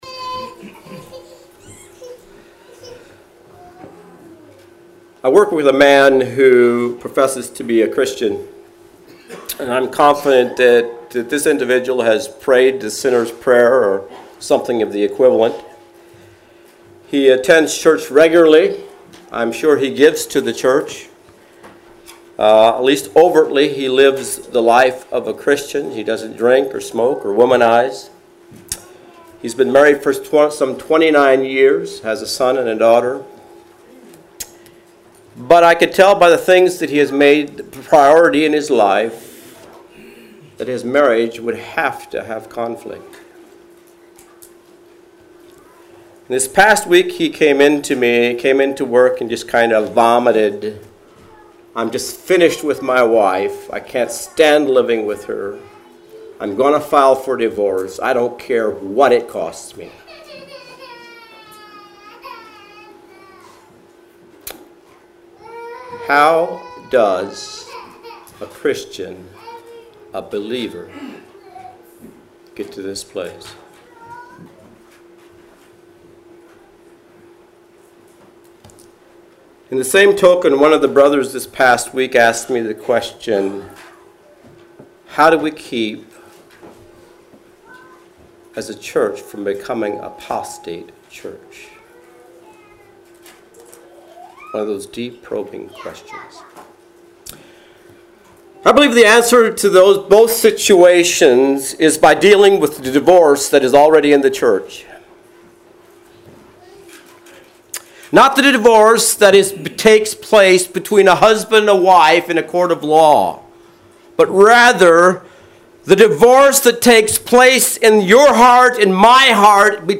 Sermons The Epistle of James